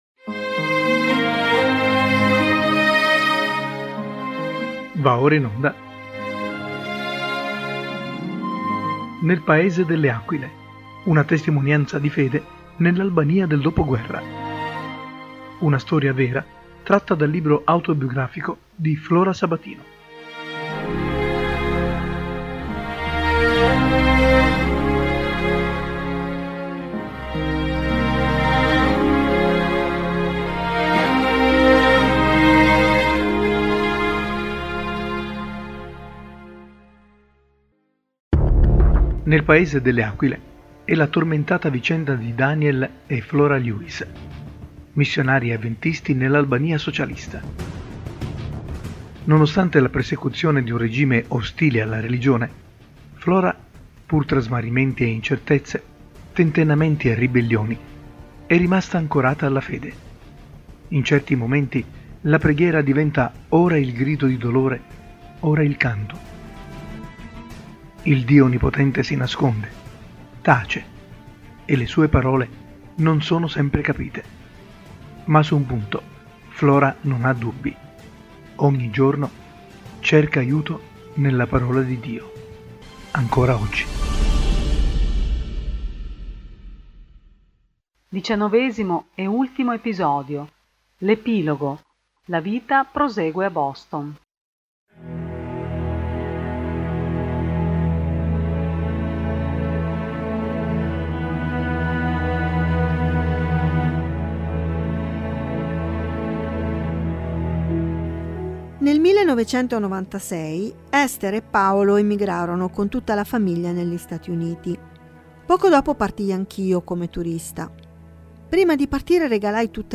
NEL PAESE DELLE AQUILE DICIANNOVESIMO EPISODIO: La vita prosegue a Boston L’EPILOGO – ULTIMO EPISODIO Nel paese delle Aquile è la lettura semi sceneggiata dell’omonimo libro edito da Edizioni ADV della collana